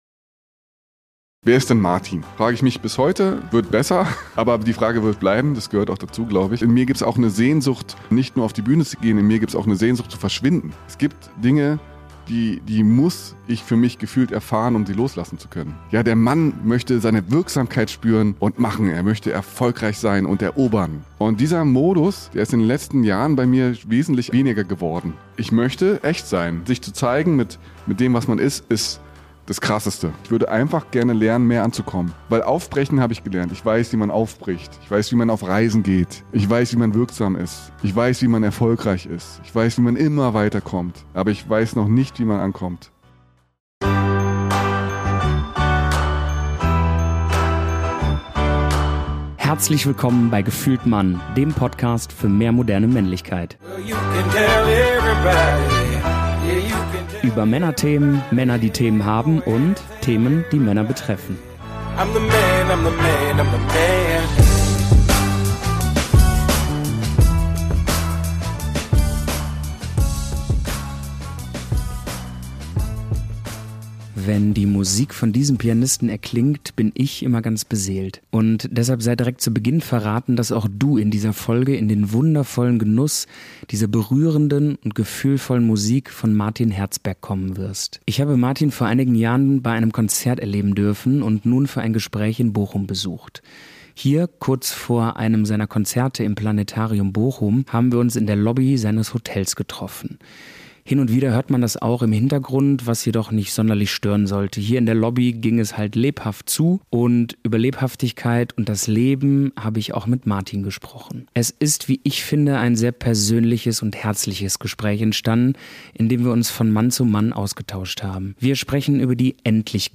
Hin und wieder hört man das auch im Hintergrund, was jedoch nicht sonderlich stören sollte. Hier in der Lobby, ging es halt lebhaft zu.